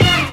SKIP HIT.wav